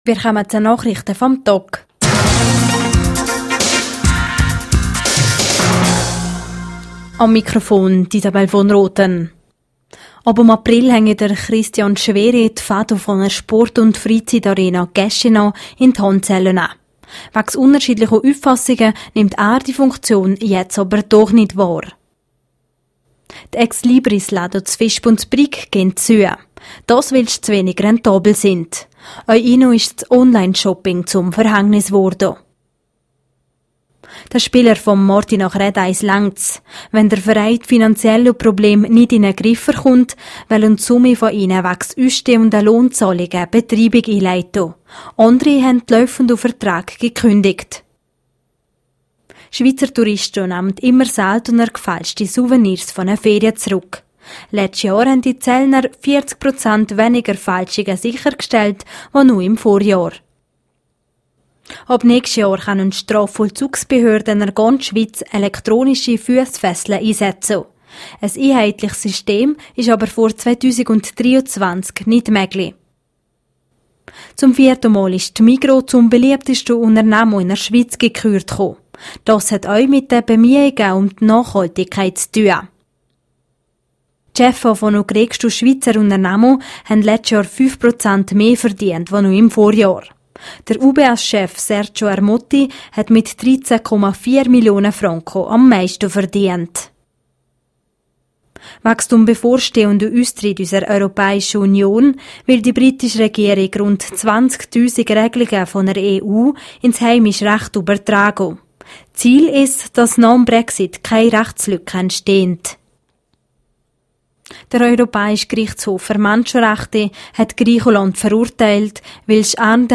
Nachrichte vam Tag (2.34MB)